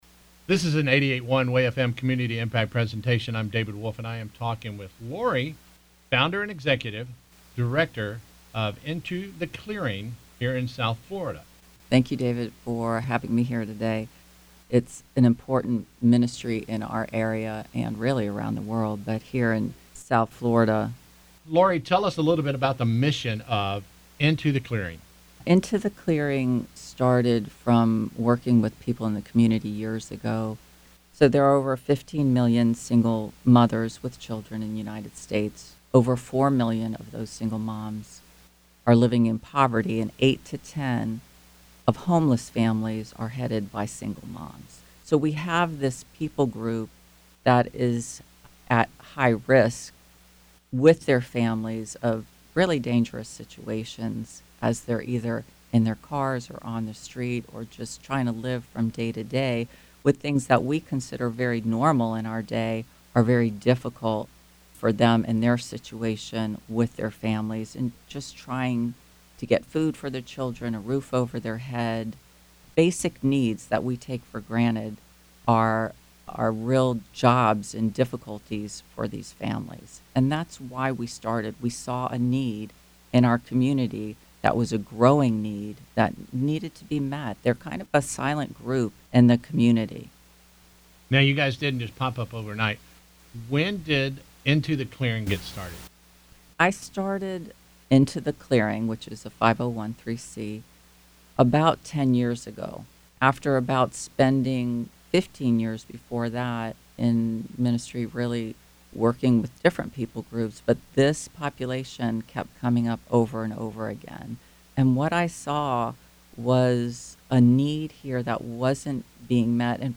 Into the Clearing WAY-FM Interview
The interview will air on WAY-FM, 88.1 throughout the Fall 2019, bringing awareness and opportunities for people in the community and beyond for those with a heart for this ministry to partner with Into The Clearing in serving these mom's and families locally.